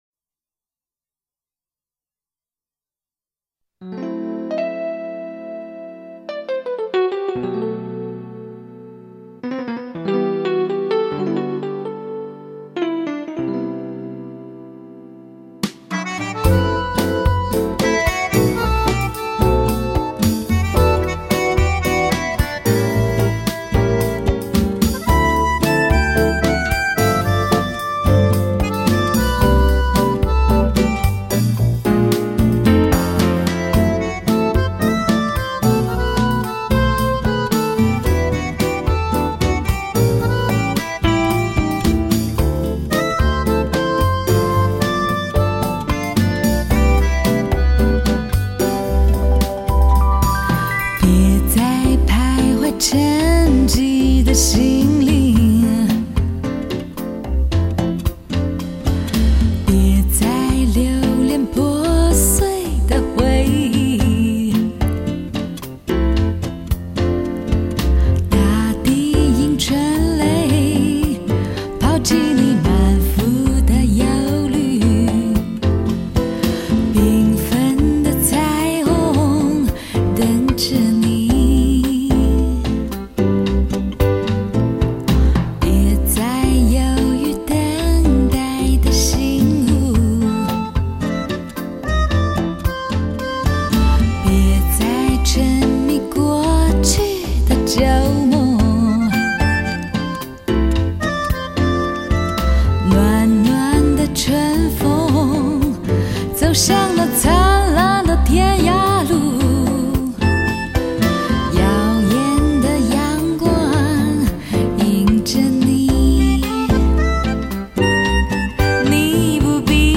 这是一张绝无仅有的爵士女声发烧大碟 拥有磁性金属的声音 深厚的唱功 情调浪漫 别具一格的爵士味道